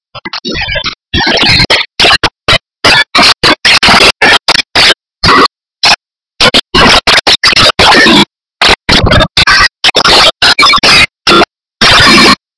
Afternoon Birds Song In Forest Sound Effect
Note: When you click preview to listen to the sound, it is distorted but the actual sound you get is perfect.
afternoon-birds-song-in-forest.mp3